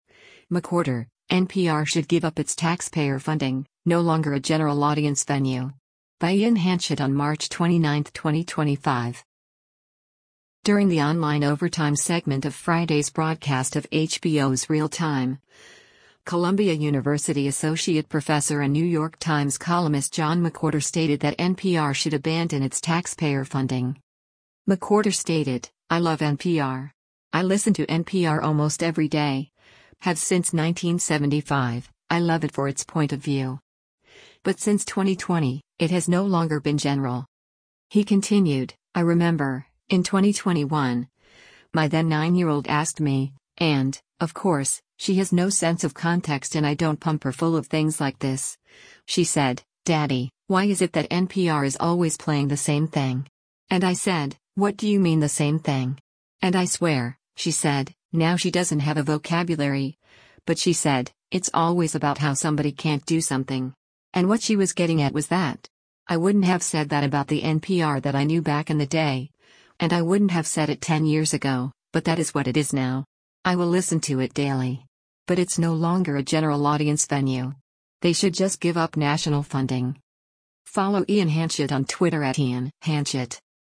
During the online “Overtime” segment of Friday’s broadcast of HBO’s “Real Time,” Columbia University Associate Professor and New York Times columnist John McWhorter stated that NPR should abandon its taxpayer funding.